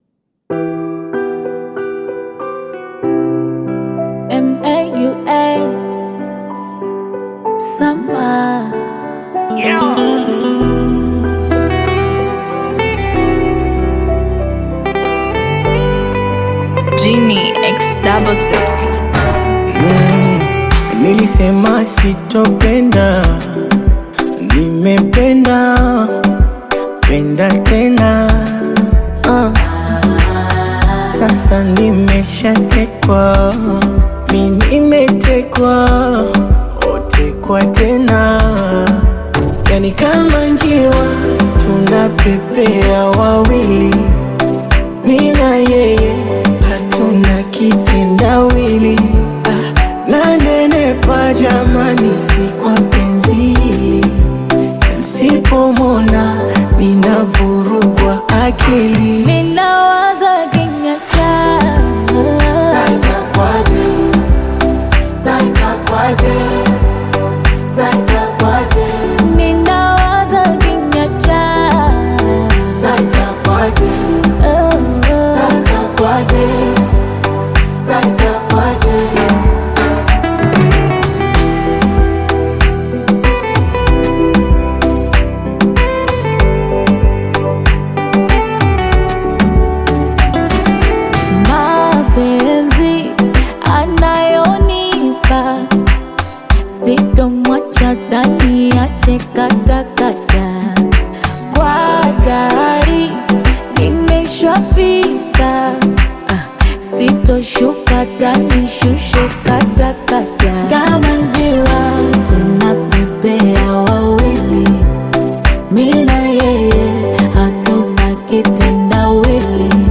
Bongo Flava music track
Bongo Flava You may also like